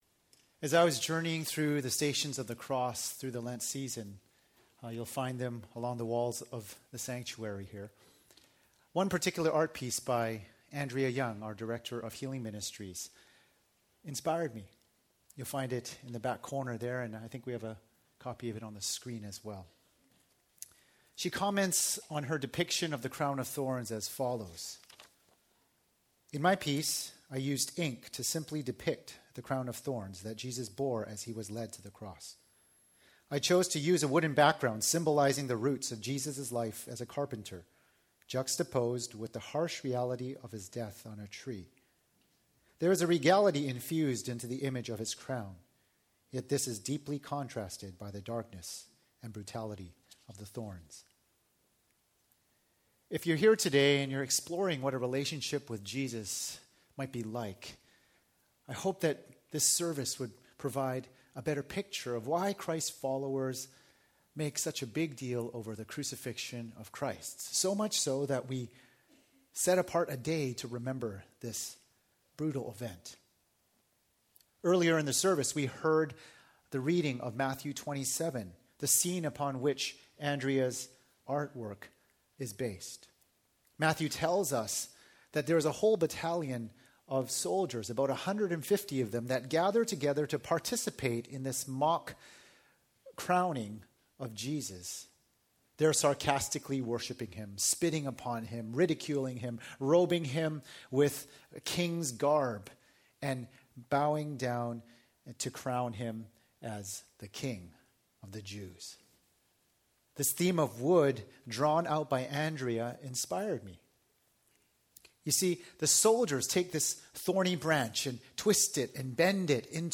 Good Friday 2016